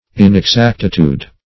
Inexactitude \In`ex*act"i*tude\, n.